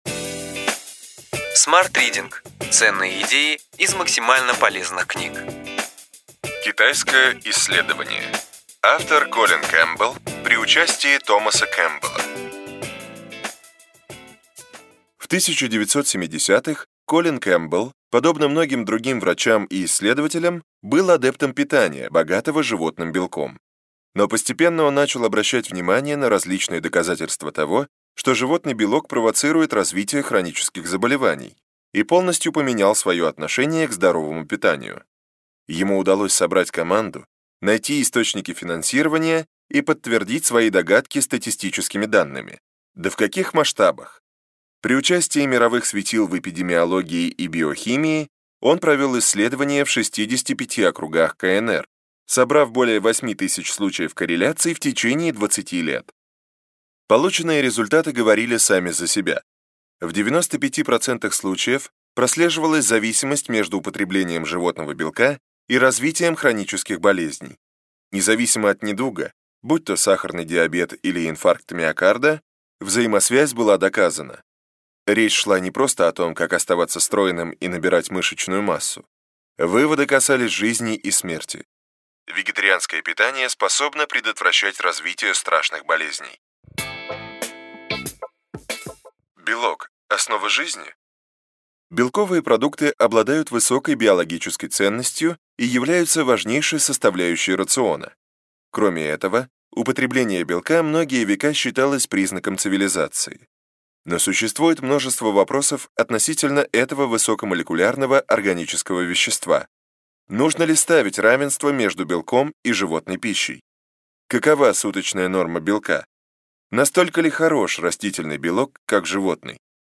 Аудиокнига Ключевые идеи книги: Китайское исследование.